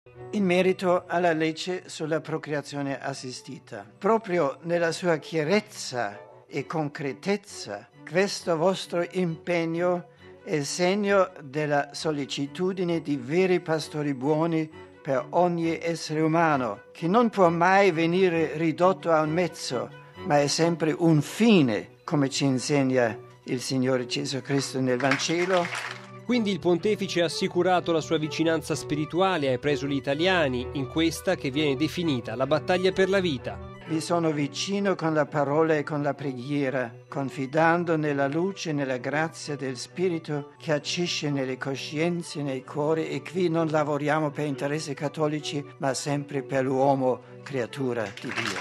Benedetto XVI nel Suo discorso ai vescovi italiani, per 54ª assemblea generale della CEI, ha rimarcato senza mezzi termini la centralità della vita, lodato l’impegno della Chiesa italiana in tal senso e alla vigilia del referendum ha ribadito RealAudio